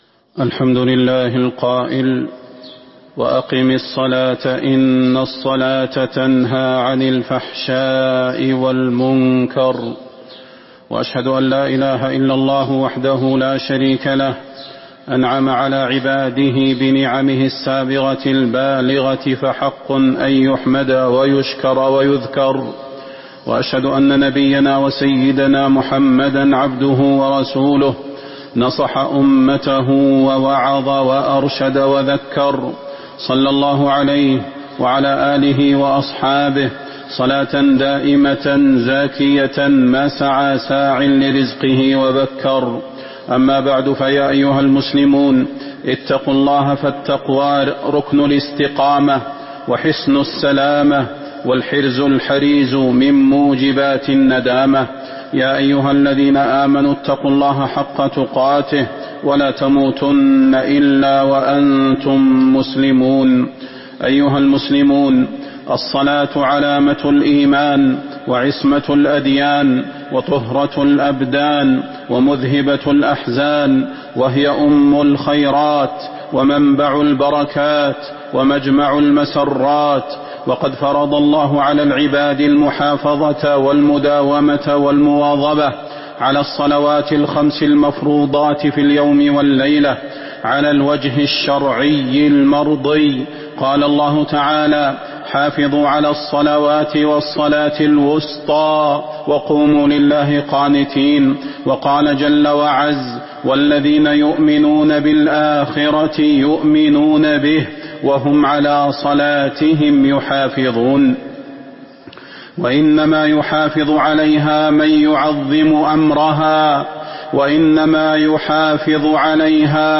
تاريخ النشر ٥ جمادى الآخرة ١٤٤٦ هـ المكان: المسجد النبوي الشيخ: فضيلة الشيخ د. صلاح بن محمد البدير فضيلة الشيخ د. صلاح بن محمد البدير الصلاة نور القلوب